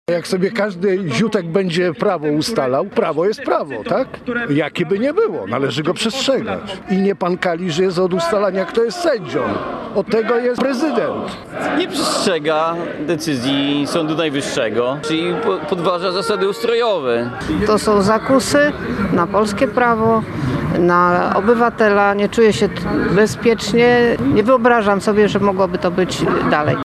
To walka o wolne wybory – mówili zwolennicy Prawa i Sprawiedliwości protestujący pod gdańską delegaturą Krajowego Biura Wyborczego. W piątkowy wieczór spotkali się w tym miejscu, by wyrazić sprzeciw wobec dalszego wstrzymywania subwencji dla partii.
Posłuchaj, co mówili naszemu reporterowi: